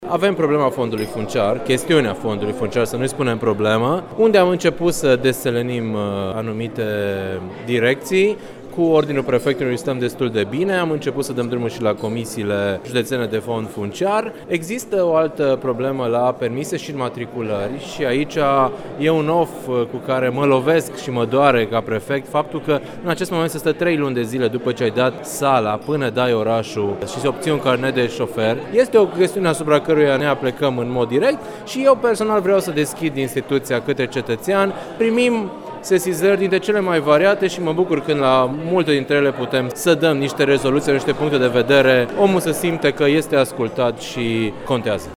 Prefectul de Timiș, Mihai Ritivoiu, spune  că la Serviciul Permise și Înmatriculări rămâne problema celor care vor să obțină un permis de circulație și sunt nevoiți să aștepte chiar și trei între proba teoretică și cea practică.
02-Mihai-Ritivoiu.mp3